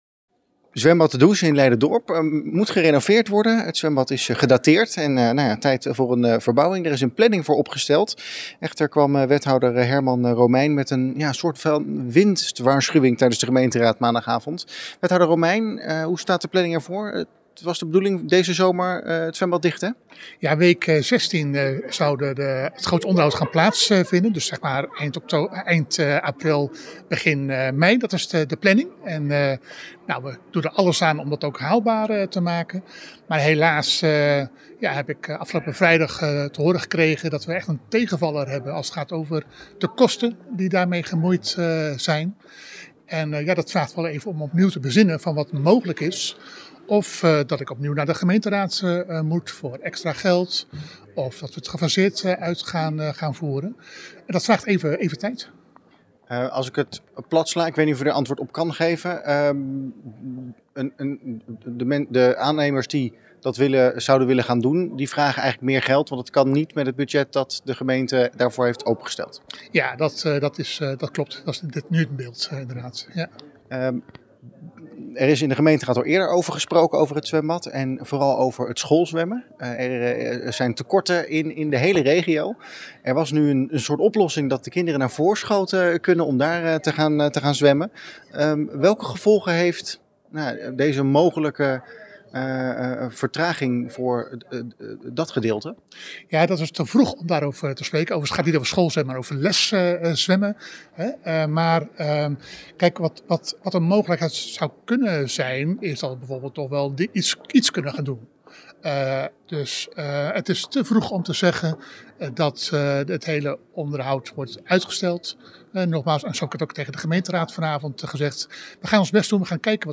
Politiek verslag
Wethouder-Romeijn-over-renovatie-De-Does.mp3